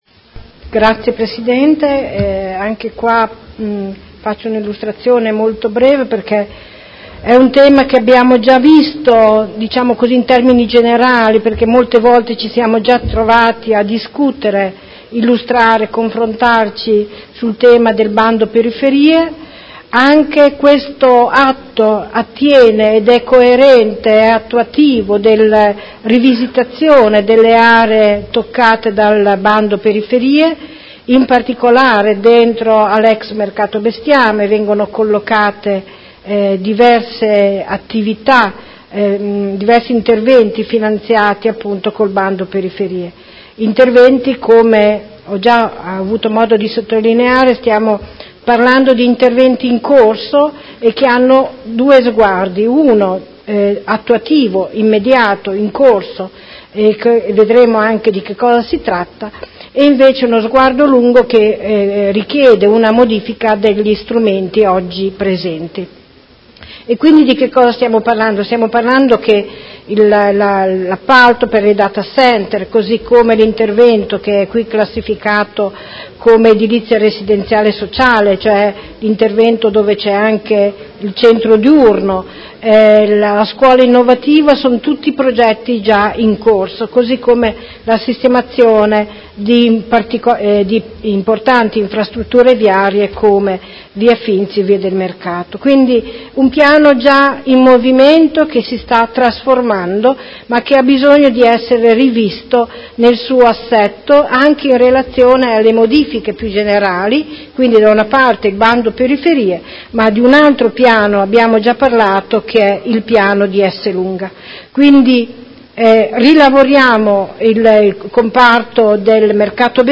Anna Maria Vandelli — Sito Audio Consiglio Comunale